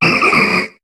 Cri d'Okéoké dans Pokémon HOME.